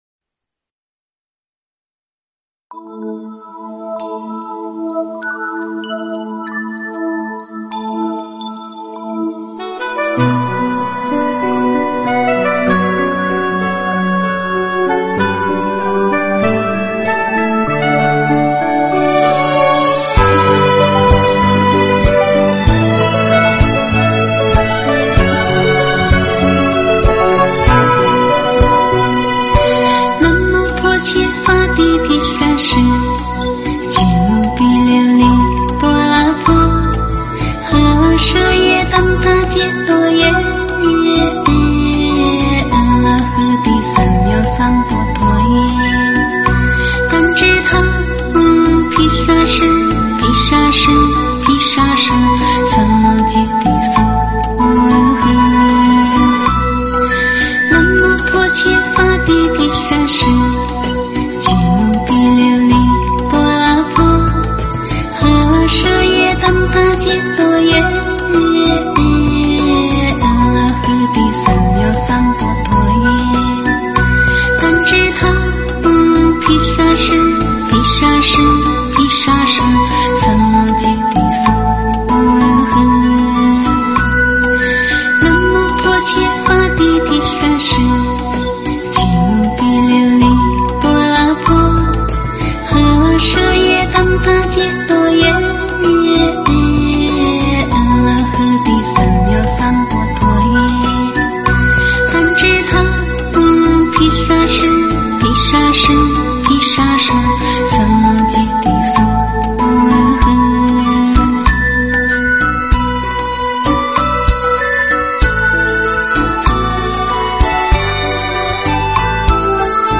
药师咒（女声唱诵版）.mp3